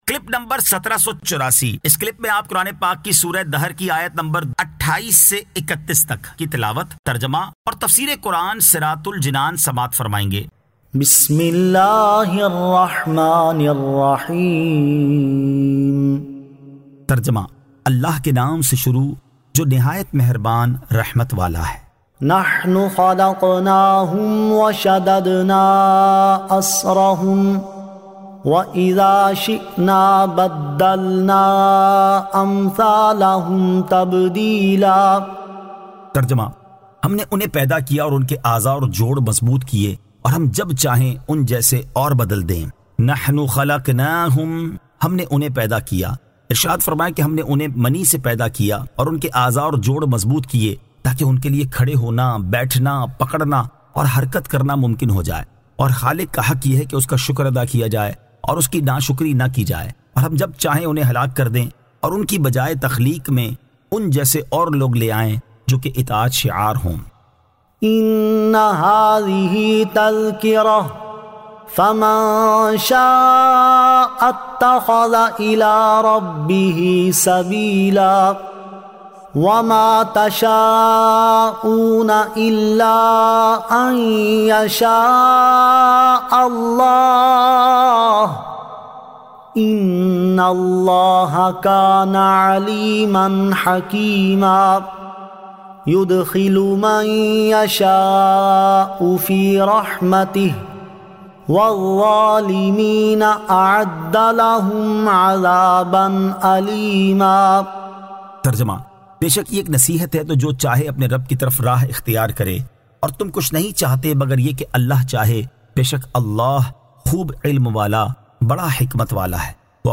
Surah Ad-Dahr 28 To 31 Tilawat , Tarjama , Tafseer